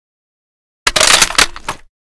bolt.ogg